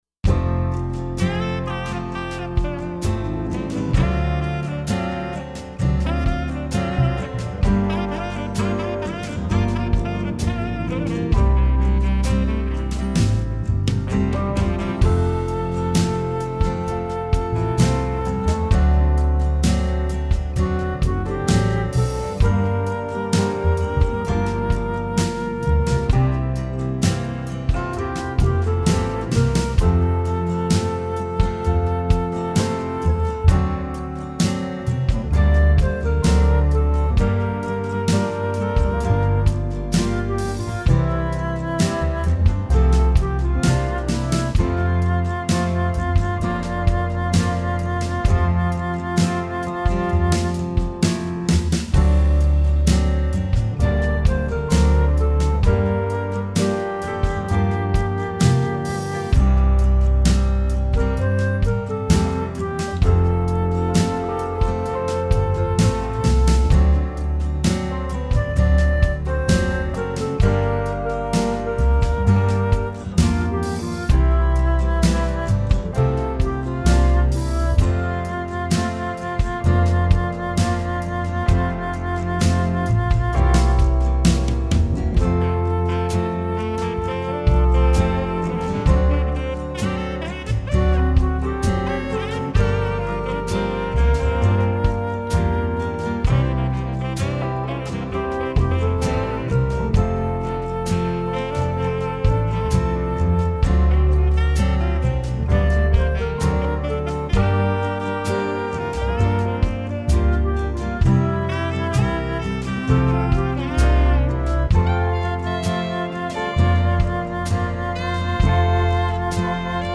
A lovely sung prayer about prayer